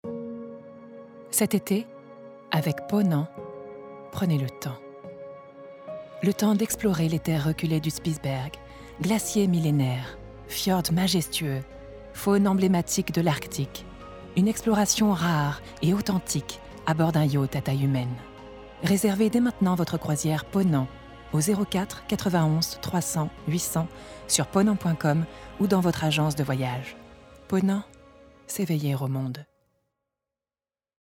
30 - 50 ans - Mezzo-soprano